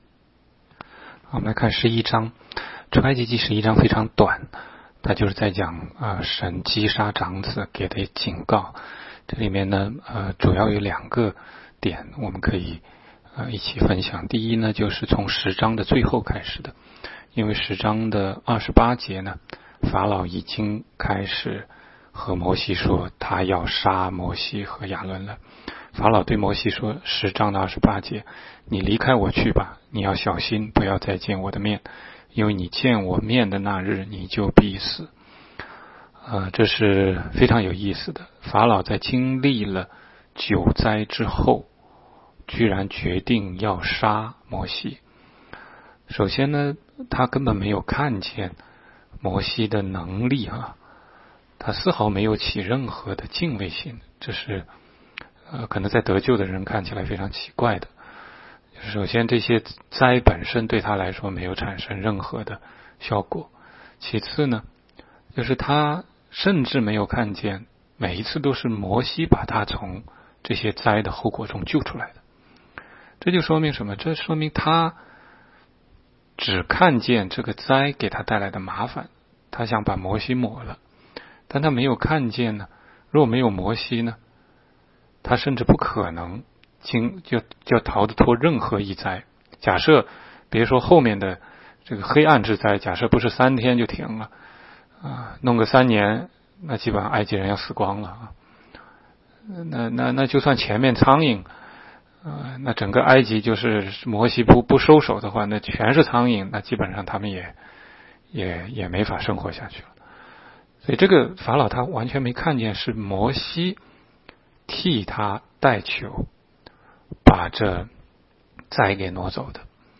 16街讲道录音 - 每日读经-《出埃及记》11章